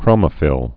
(krōmə-fĭl)